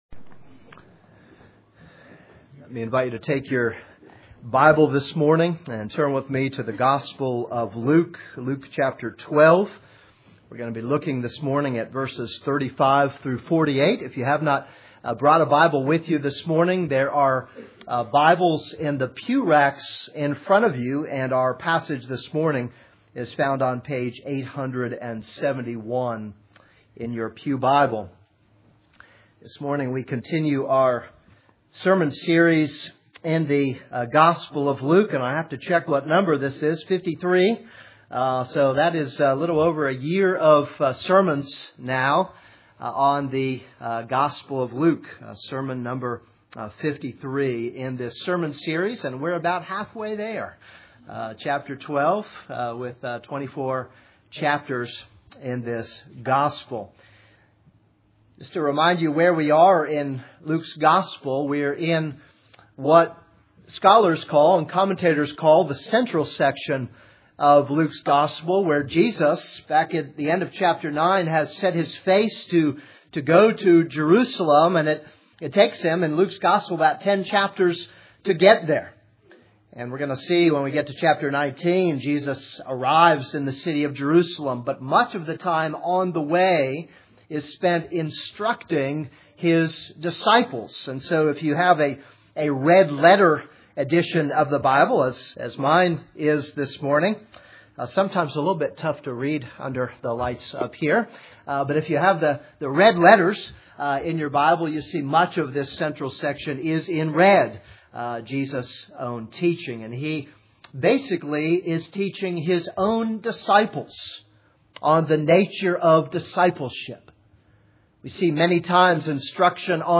This is a sermon on Luke 12:35-48.